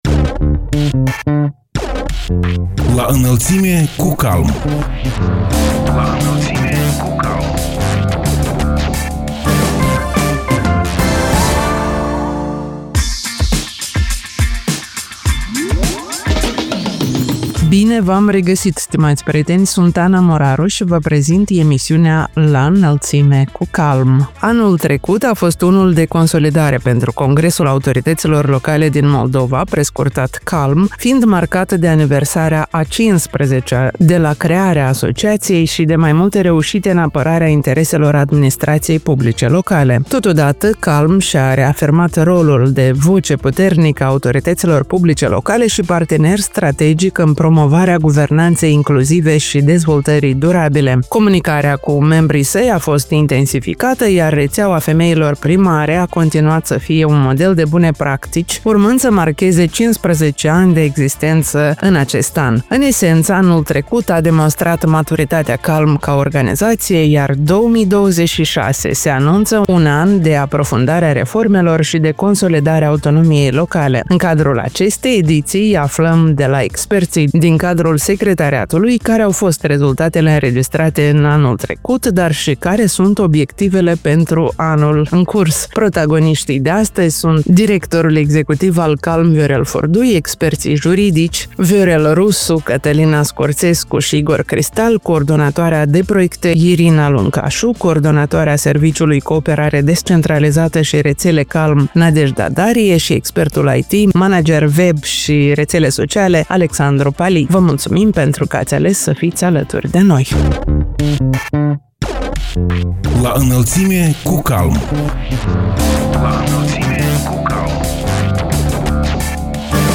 În cadrul emisiunii „La Înălțime cu CALM” aflăm de la experții din cadrul Secretariatului mai multe despre rezultatele înregistrate, dar și despre obiectivele pentru acest an.